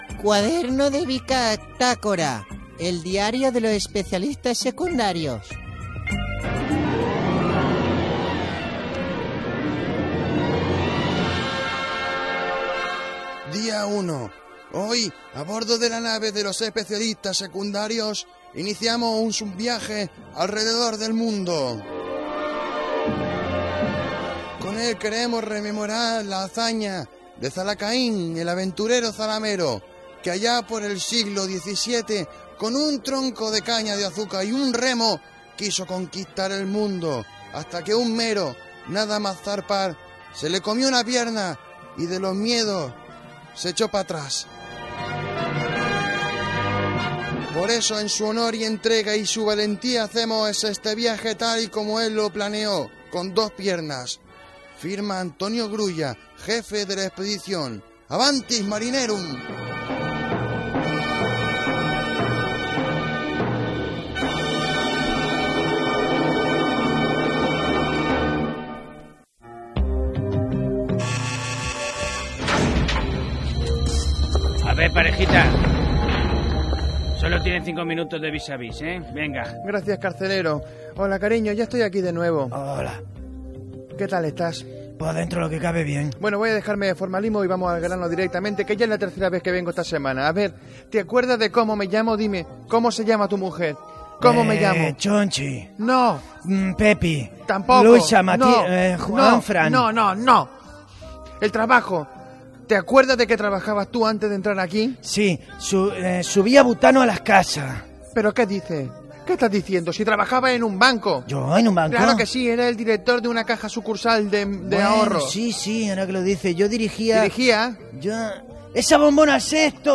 Un viatge humorístic al voltant del món. Dues escenes: en una presó i en un consultori mèdic.
Gènere radiofònic Entreteniment